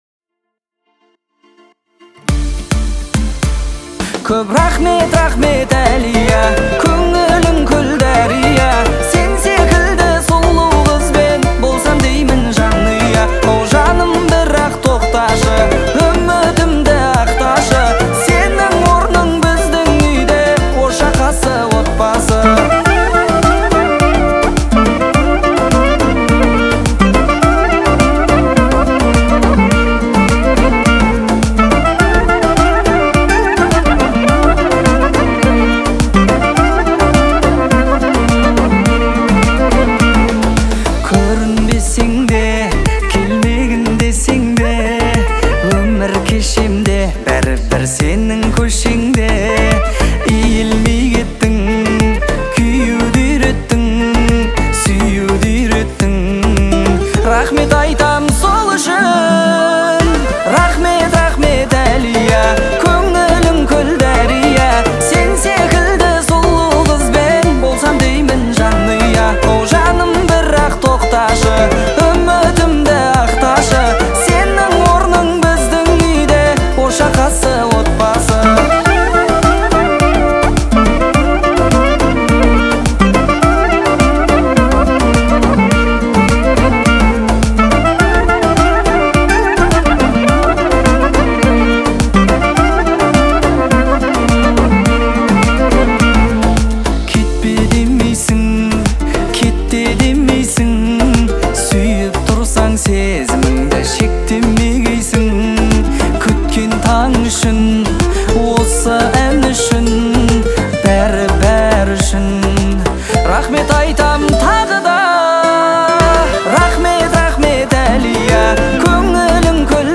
это эмоциональная песня в жанре казахского попа